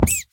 1.21.5 / assets / minecraft / sounds / mob / rabbit / hurt3.ogg
hurt3.ogg